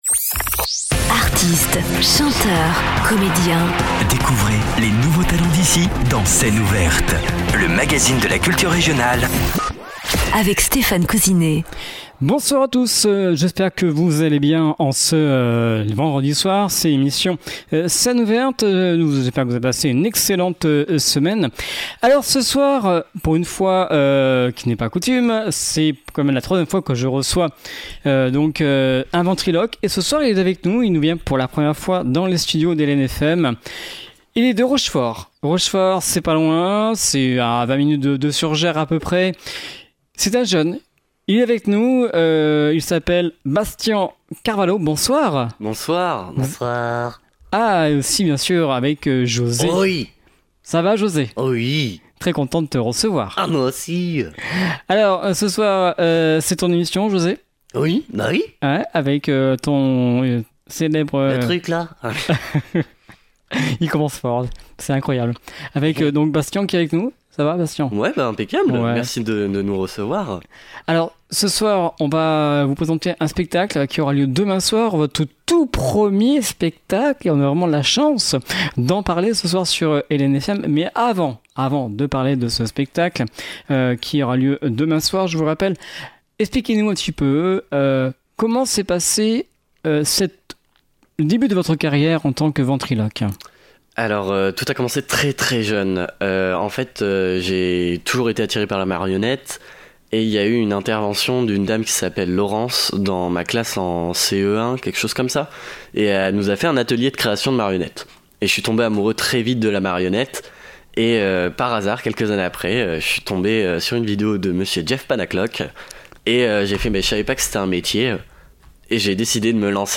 avec le ventriloque